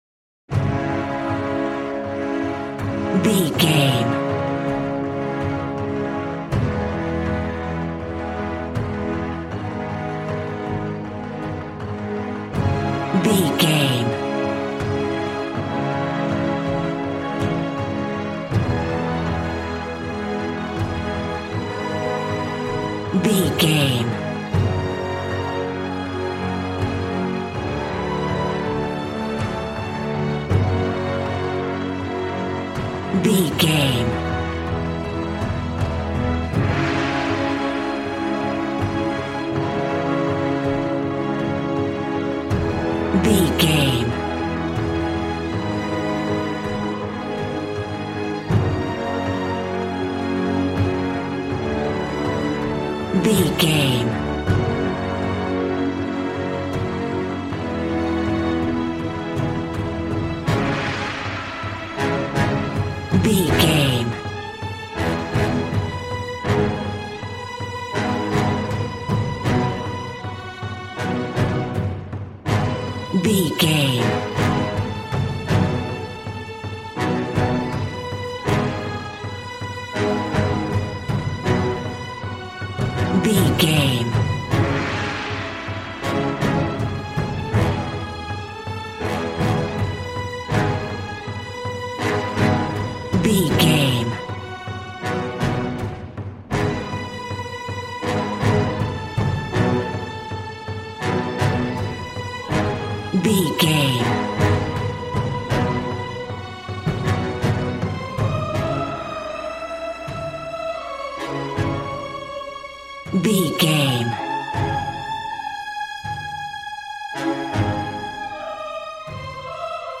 Action and Fantasy music for an epic dramatic world!
Aeolian/Minor
A♭
hard
groovy
drums
bass guitar
electric guitar